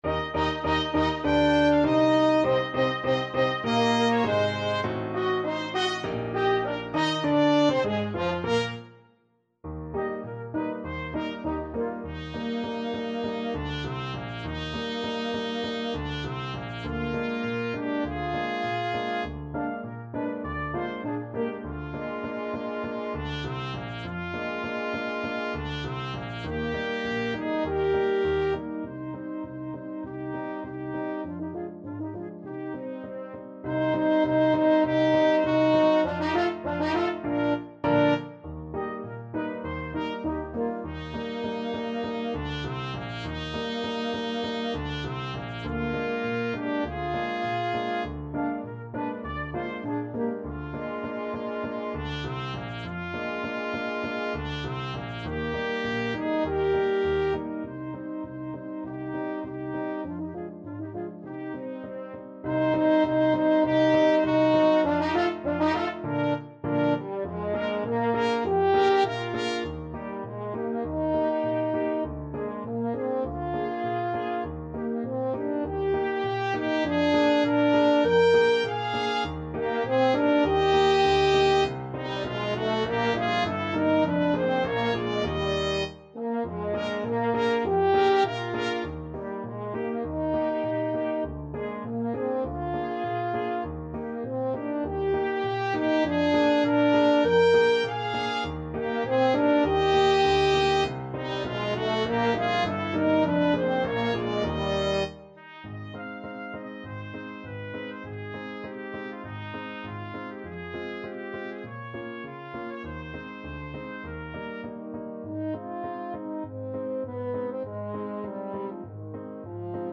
(with piano)
Marziale = c. 100
2/2 (View more 2/2 Music)